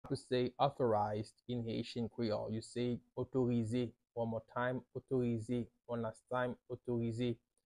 “Authorize” in Haitian Creole – “Otorize” pronunciation by a native Haitian teacher
“Otorize” Pronunciation in Haitian Creole by a native Haitian can be heard in the audio here or in the video below:
How-to-say-Authorize-in-Haitian-Creole-–-Otorize-pronunciation-by-a-native-Haitian-teacher.mp3